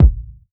Kick Single Hit A# Key 561.wav
Royality free bass drum sample tuned to the A# note. Loudest frequency: 107Hz
kick-single-hit-a-sharp-key-561-PTs.wav